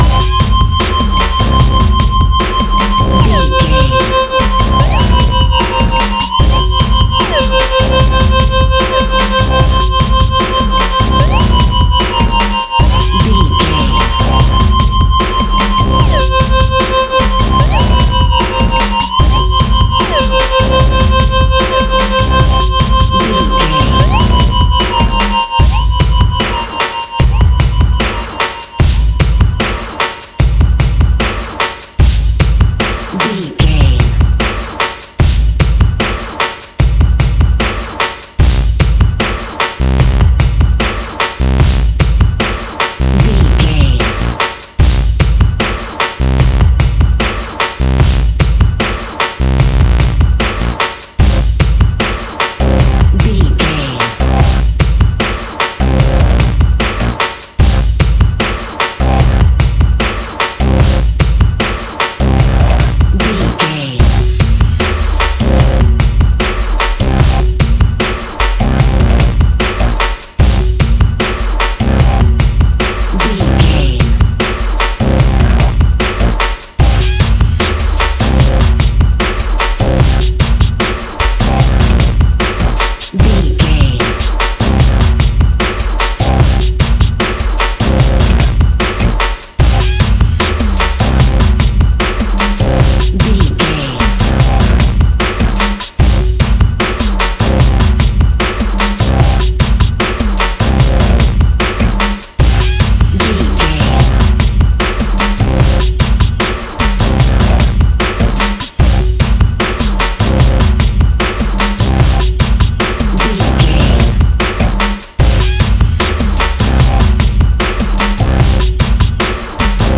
Epic / Action
Fast paced
Aeolian/Minor
Fast
hard
intense
energetic
driving
repetitive
dark
synthesiser
drums
drum machine
electronic
techno
industrial
glitch